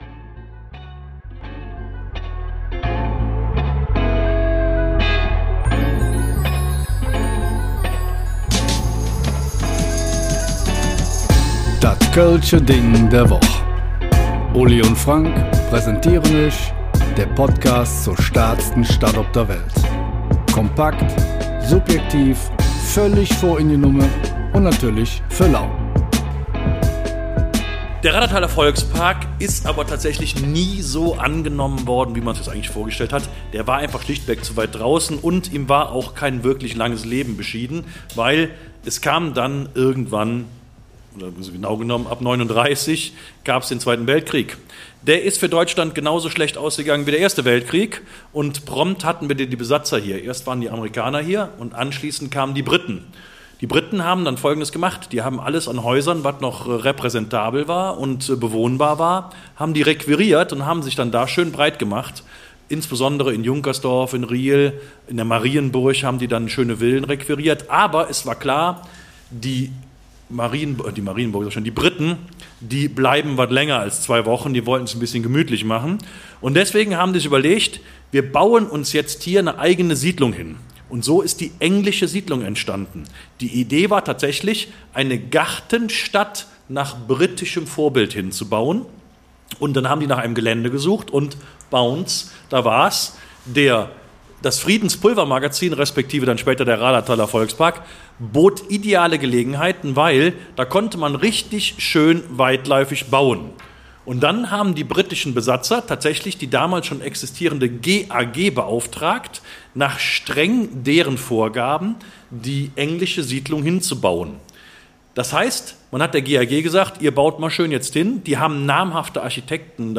Raderberg – Kloster, Deutsche Welle und Siedlung Wilhemsruh (live vor Publikum) Heute kommt Teil II unseres Experiments, zwei Folgen vom „Köln-Ding der Woche“ live vor Publikum aufzunehmen.